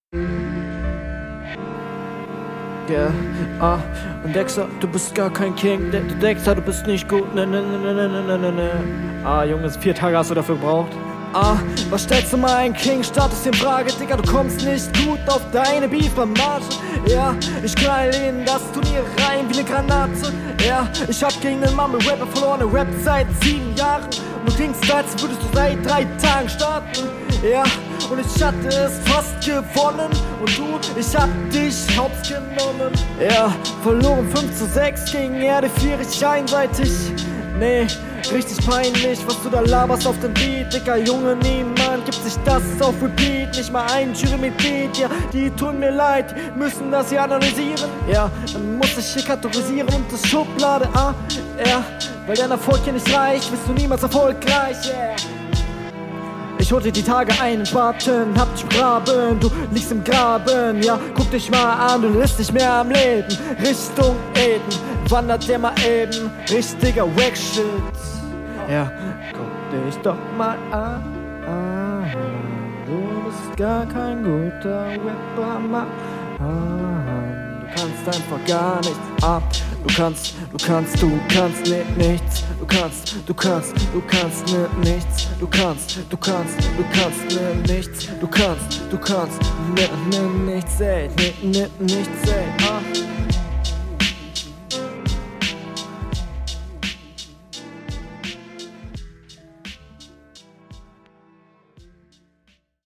Große Steigerung was den Stimmeinsatz und die abmische angeht.
Intro gut haha Uh stimmlich schon mehr Druck.